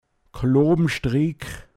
pinzgauer mundart
Kloo(b)mstriik, m. Klobenstrick, Seil zum Niederbinden des Heues